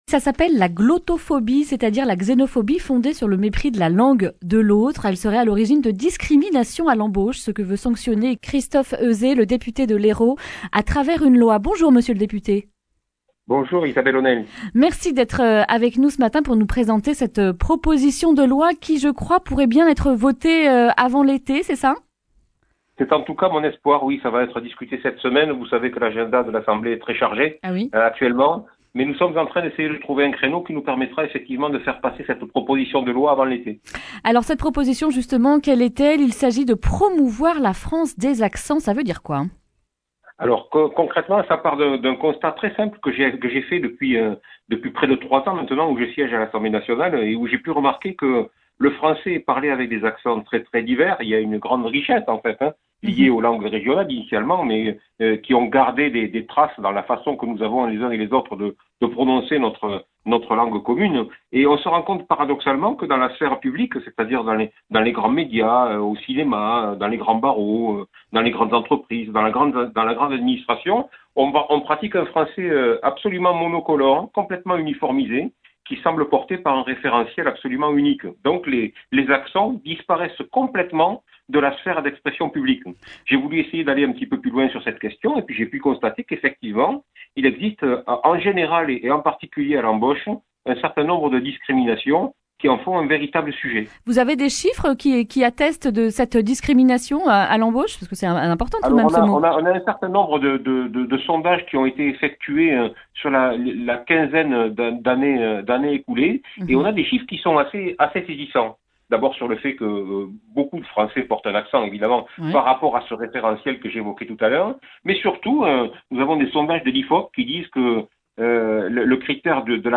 mercredi 19 février 2020 Le grand entretien Durée 11 min